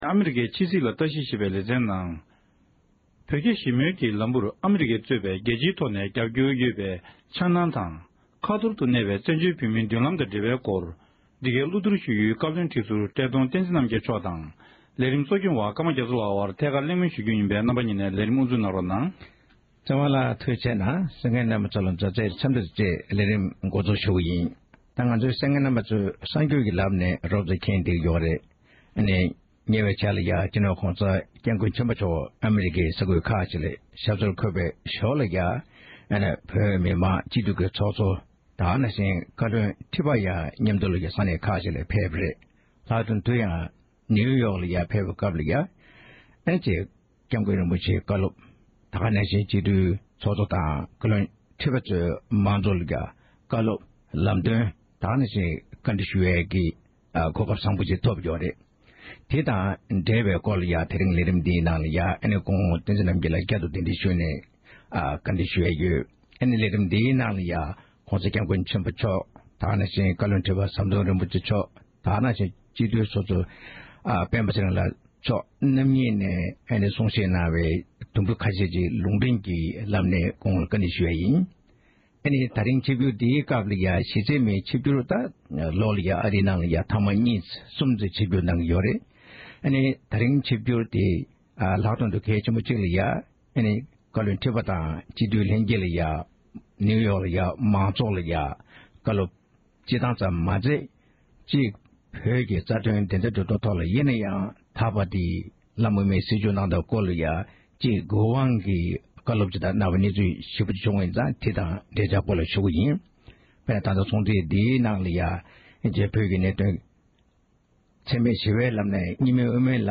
བཀའ་བློན་ཁྲི་ཟུར་བཀྲས་མཐོང་བསྟན་འཛིན་རྣམ་རྒྱལ་མཆོག་དབུ་མའི་ལམ་དང་བཙན་བྱོལ་བོད་མིའི་མདུན་ལམ་སྐོར་གླེང་བ།
གླེང་མོལ་གནང་བར་གསན་རོགས༎